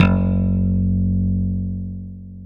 KW FUNK  F#1.wav